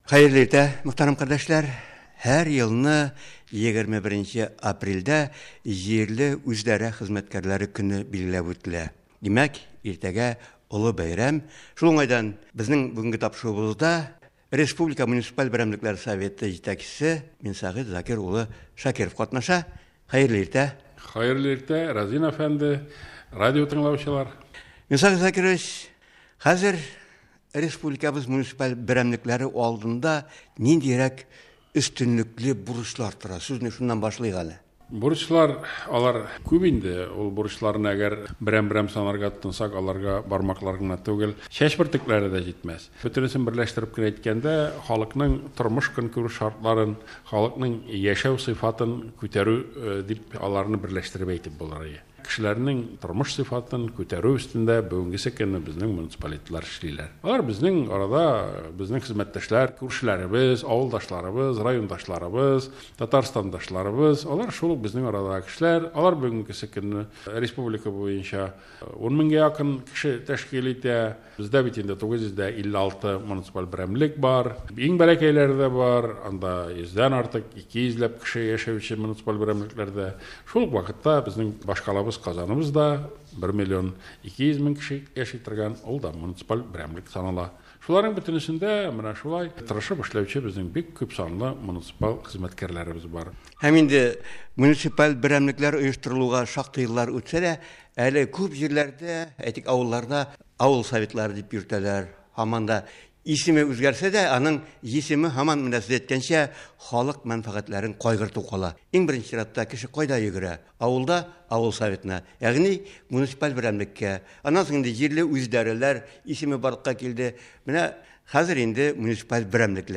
“Татарстан радиосы”нда кунакта